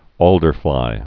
(ôldər-flī)